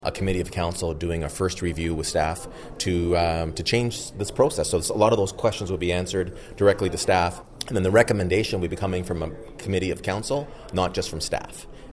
Mayor Mitch Panciuk says he’s particularly pleased with some staff additions at the Quinte Sports and Wellness Centre.